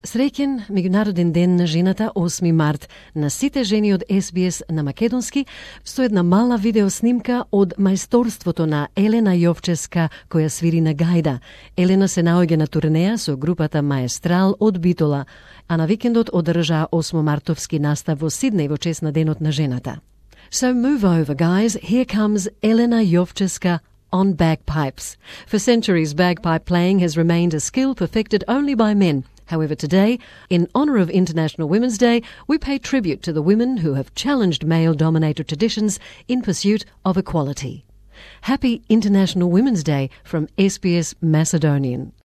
playing bagpipes